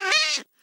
豹猫受伤时随机播这些音效（与受伤的音效相同）
Minecraft_cat_hitt2.mp3